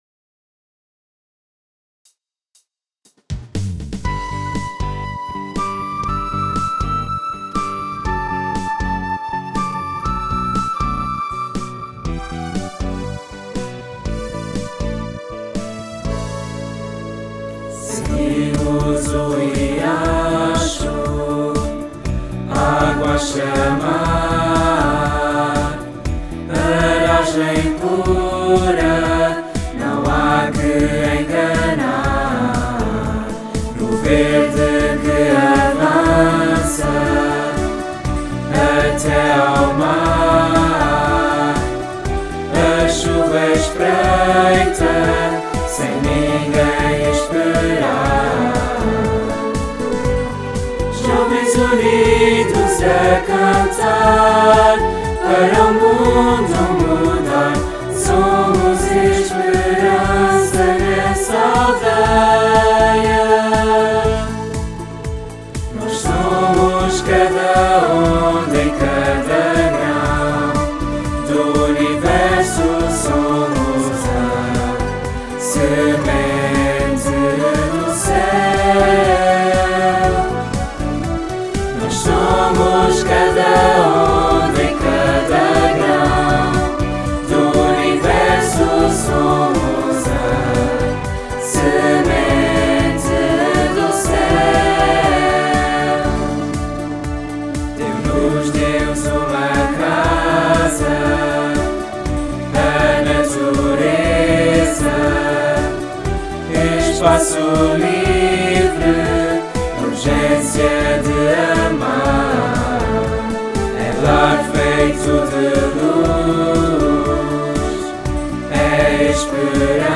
hino